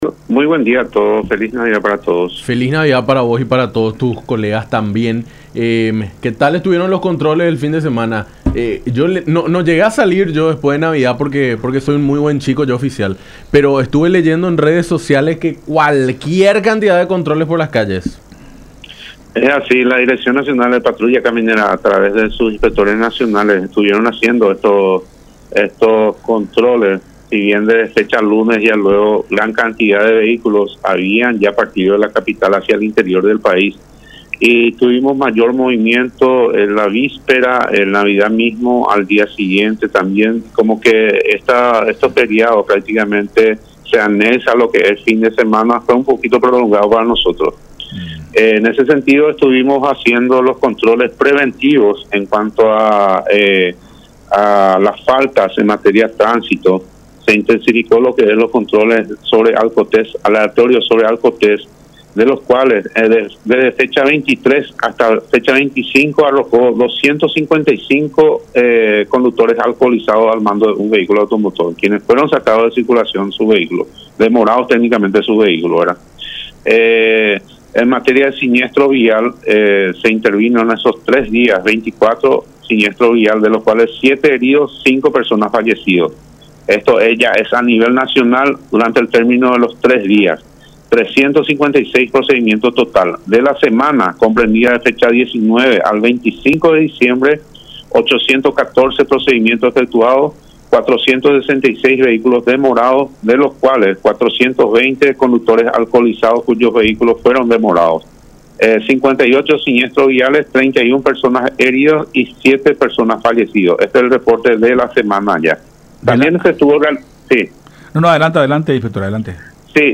en diálogo con Enfoque 800 por La Unión.